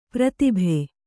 ♪ pratibhe